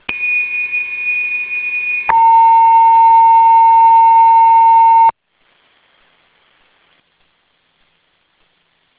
PortEwenAlarmTone.wav